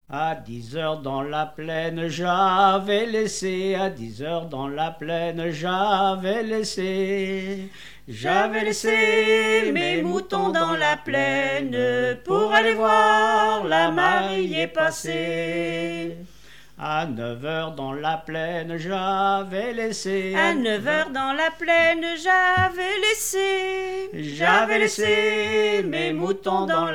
Mémoires et Patrimoines vivants - RaddO est une base de données d'archives iconographiques et sonores.
Genre énumérative
déroulement des noces
Pièce musicale inédite